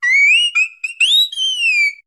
Cri de Plumeline Style Hula dans Pokémon HOME.
Cri_0741_Hula_HOME.ogg